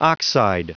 Prononciation du mot oxide en anglais (fichier audio)
Prononciation du mot : oxide